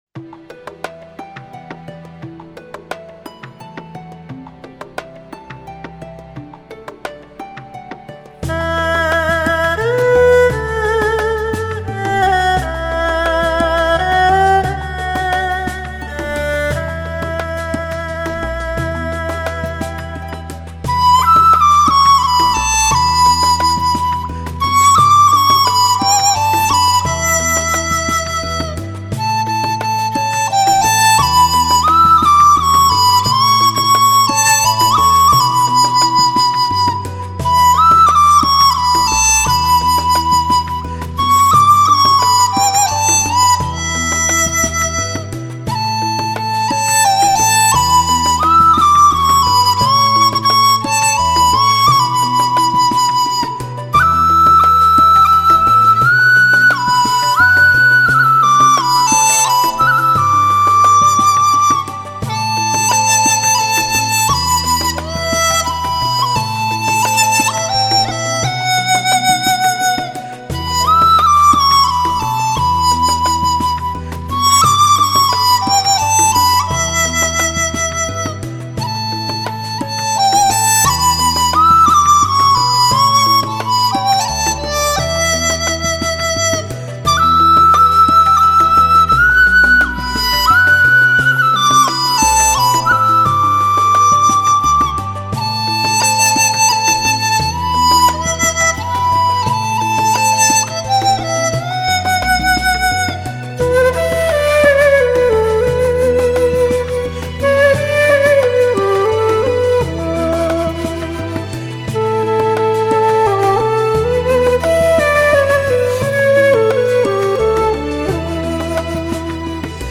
笛子和箫的优美对话，谱写了中国民乐的诗情画意
笛的悠扬 箫的深沉 奏出了都市的喧嚣 红尘的无奈 情与缘的凄美 ........